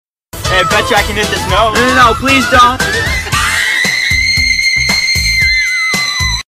mmd-x-fnaf-foxy-can-hit-all-the-high-notes.mp3